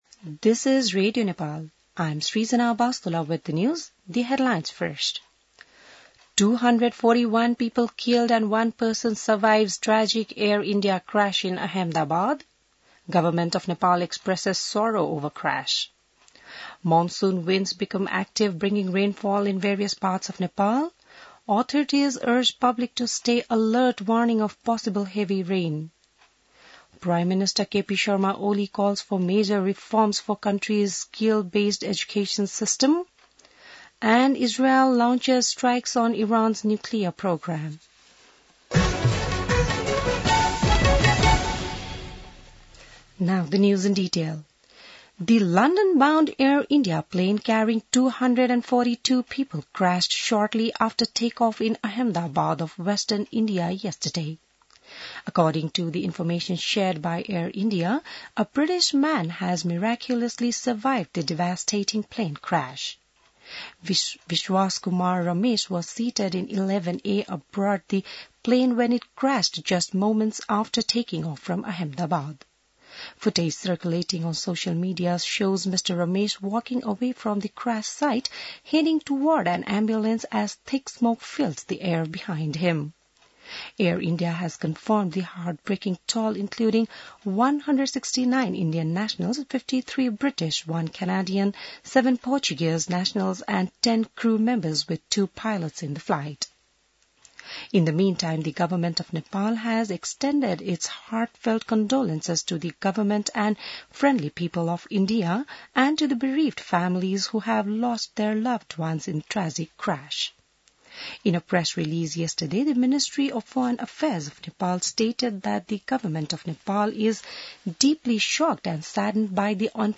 बिहान ८ बजेको अङ्ग्रेजी समाचार : ३० जेठ , २०८२